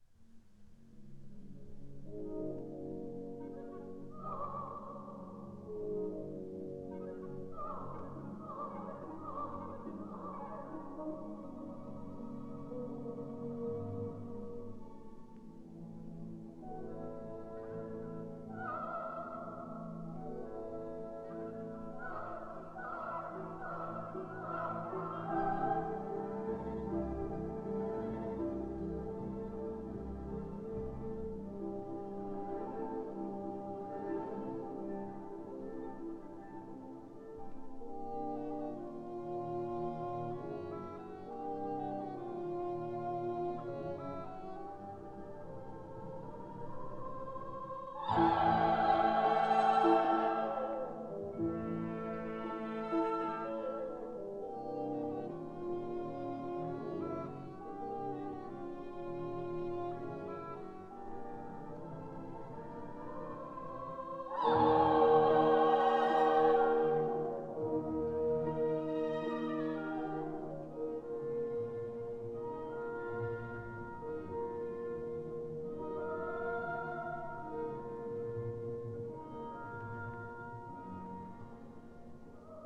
conductor
1958 stereo recording